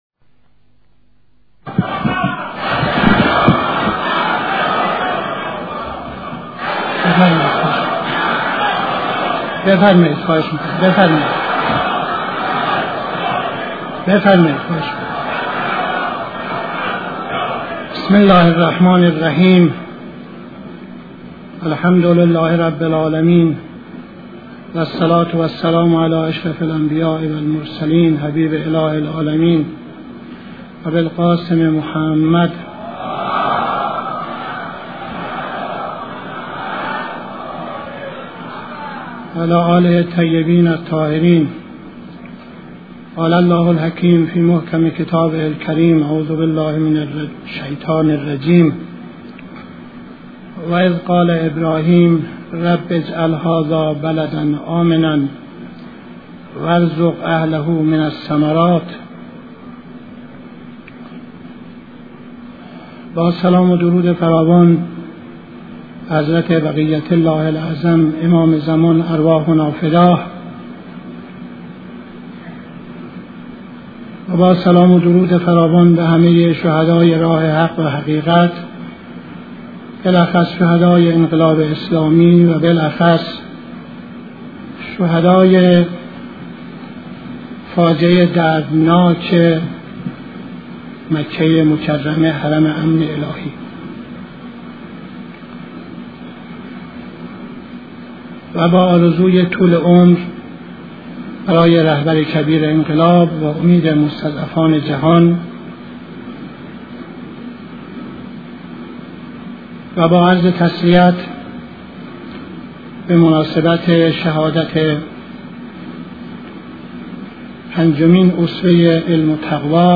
قبل از خطبه‌های نماز جمعه تهران 31-04-67